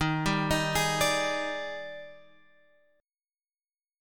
D# Major 11th